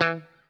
BASSS_3.WAV